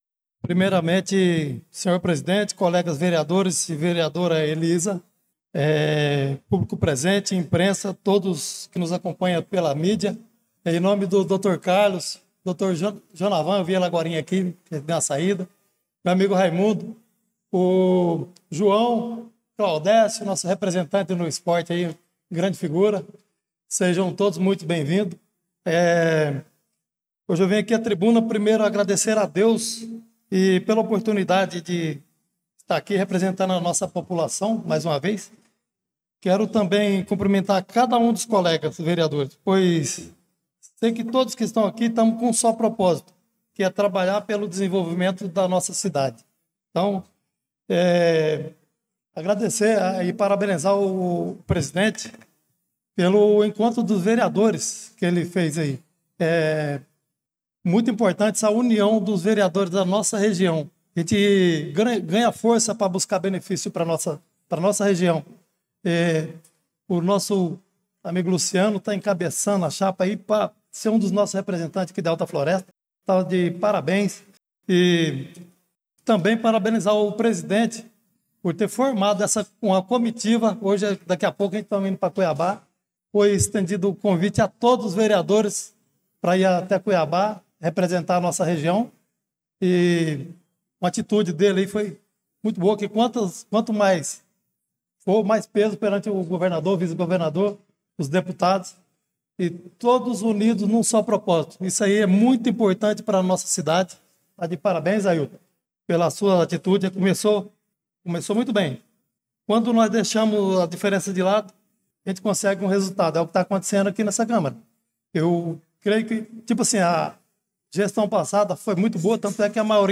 Pronunciamento do vereador Chicão do Motocross na Sessão Ordinária do dia 11/02/2025